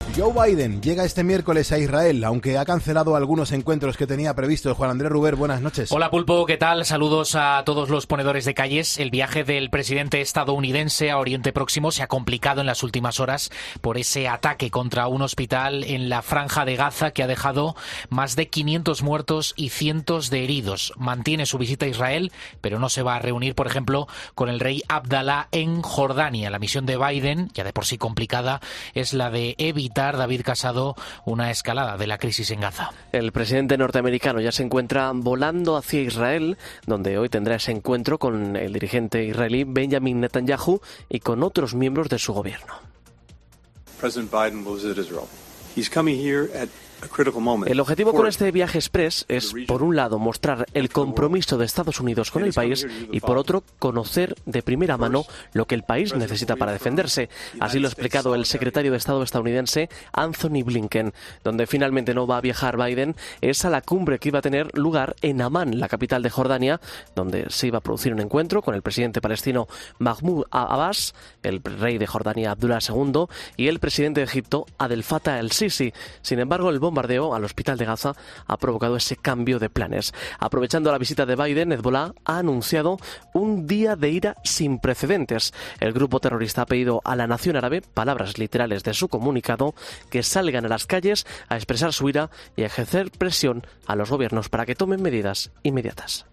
Carlos Herrera, director y presentador de 'Herrera en COPE', comienza el programa de este jueves analizando las principales claves de la jornada que pasan, entre otras cosas, las declaraciones de Yolanda Díaz.